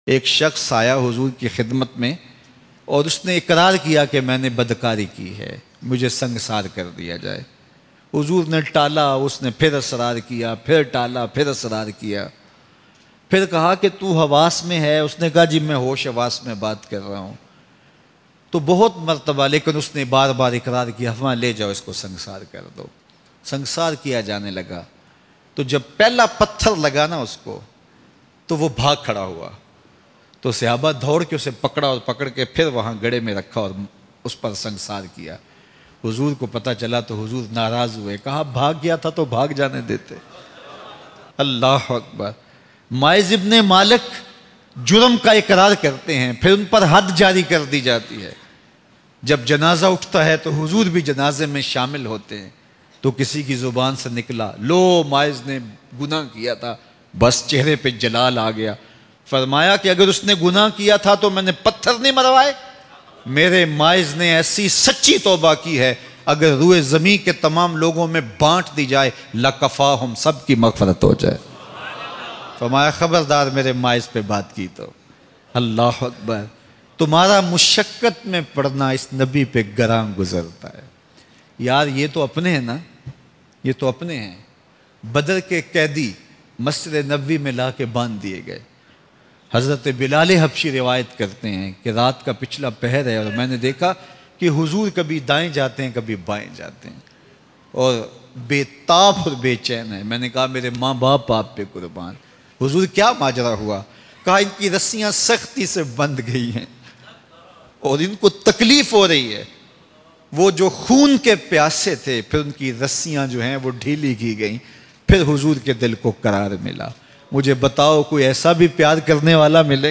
HUZOOR Me Zina kr betha hon Bayan MP3